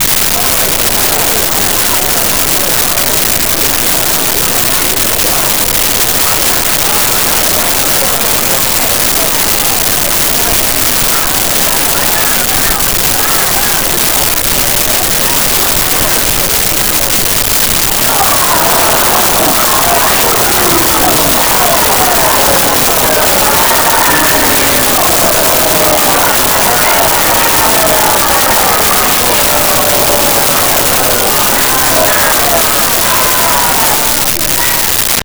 Crowd Angry Boos 01
Crowd Angry Boos 01.wav